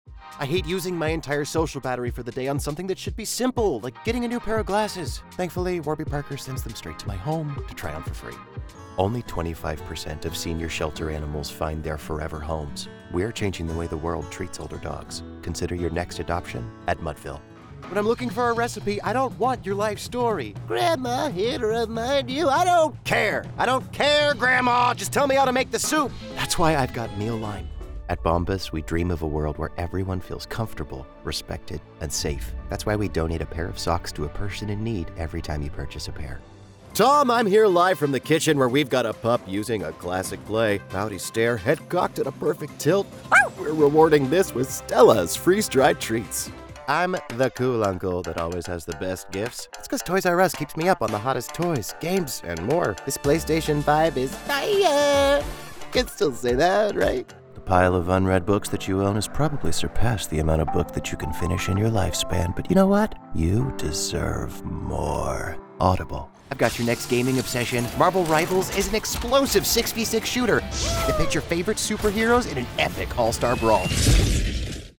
Englisch (US)
Warme, witzige und verspielte Voiceover.
Warm
Konversation
Wunderlich